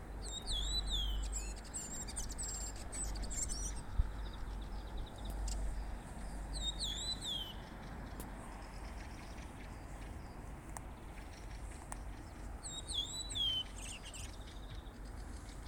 Fikk ikke sett den, men den sang lenge, og den kunne høres på lang avstand. På Hadeland 12. april.